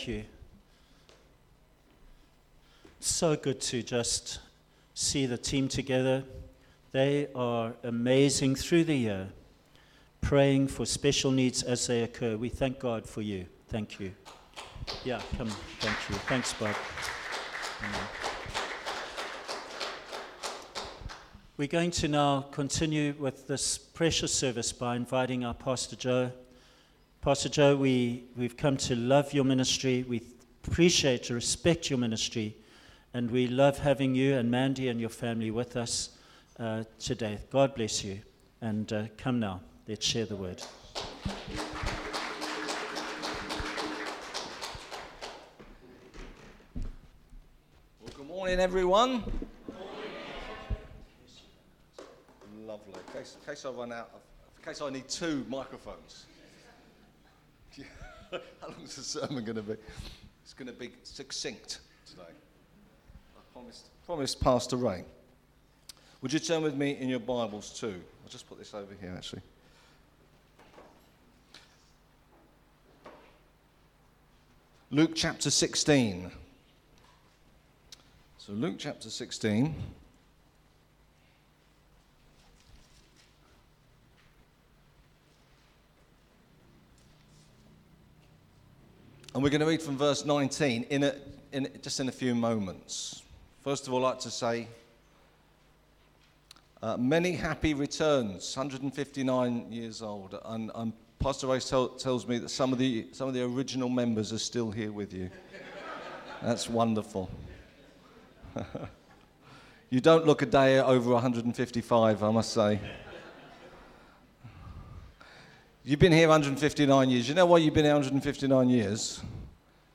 A Sunday sermon by guest speaker